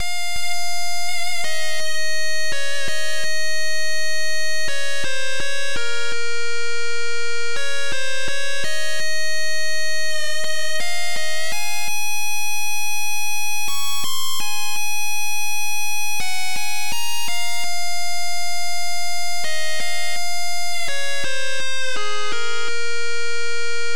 Impulse Tracker Module